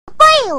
kirby-poyo.ogg